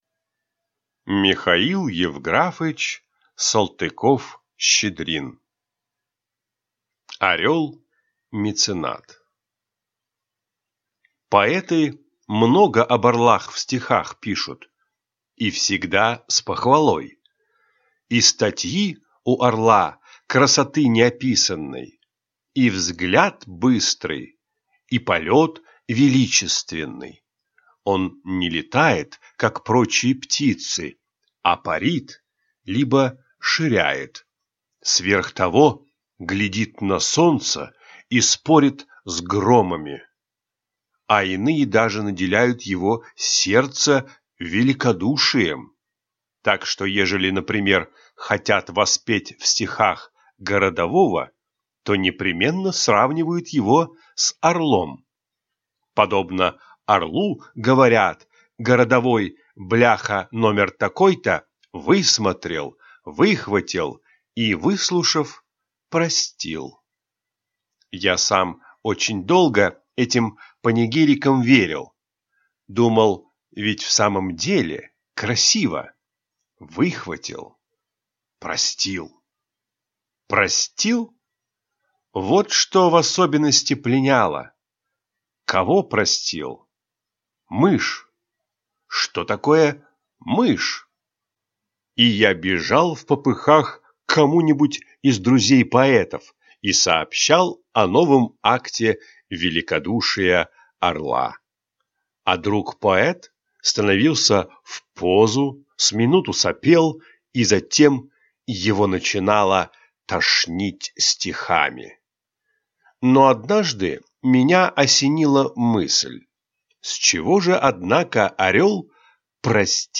Аудиокнига Орел-меценат | Библиотека аудиокниг